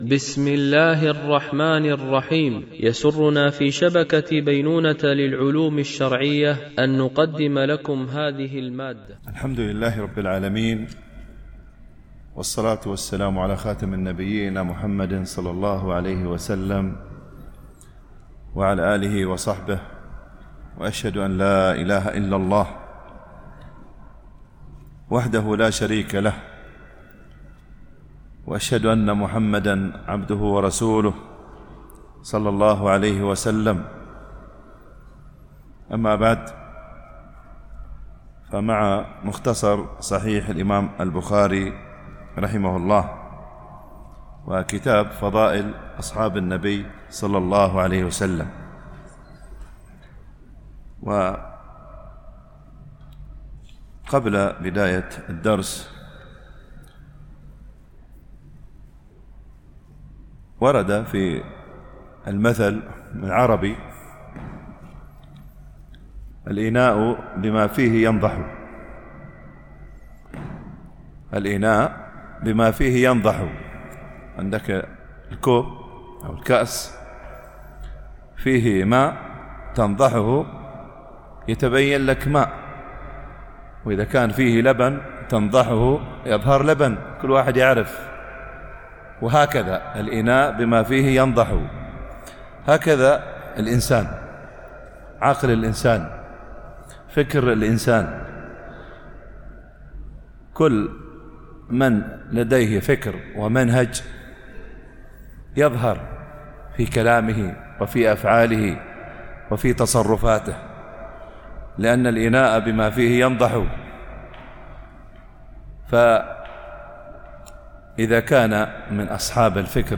شرح مختصر صحيح البخاري ـ الدرس 258